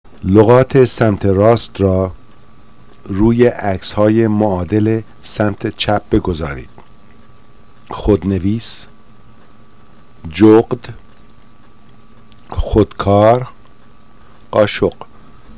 Listen to directions and the words pronounced!